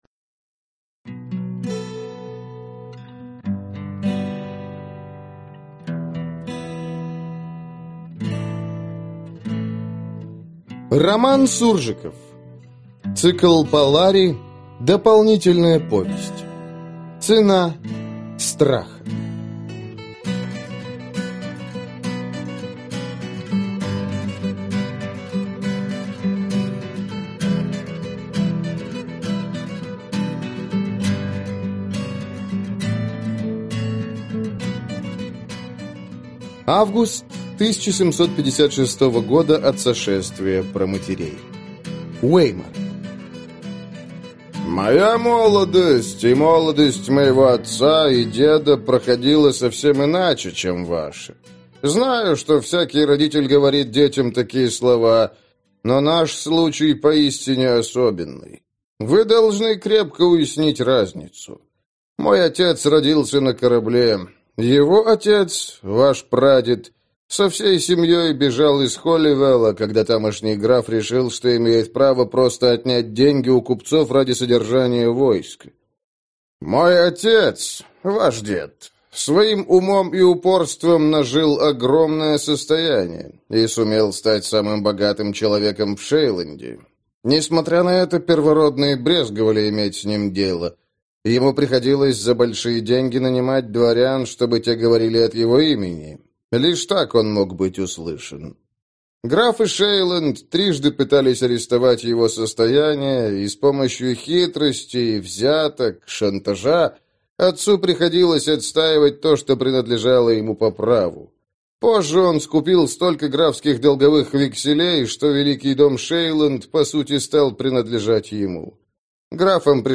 Аудиокнига Цена страха | Библиотека аудиокниг